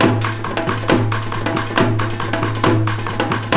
KOREA PERC 4.wav